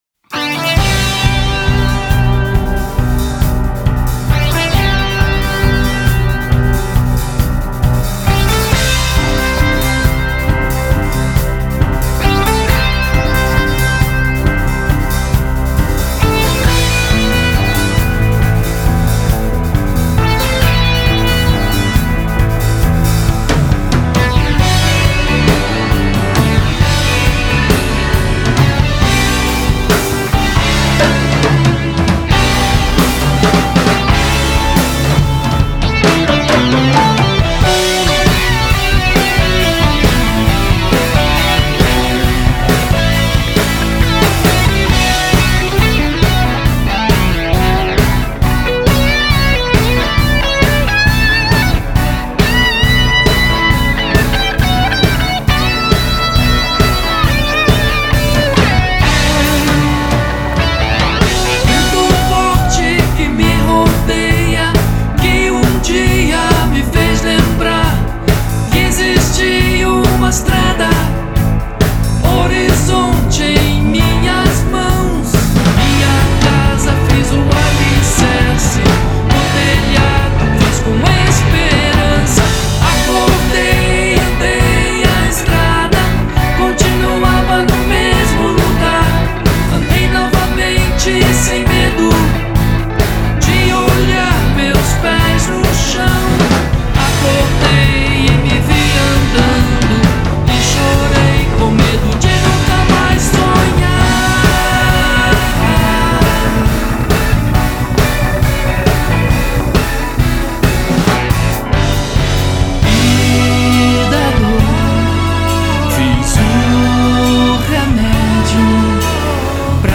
MPB Contemporanea MPB moderna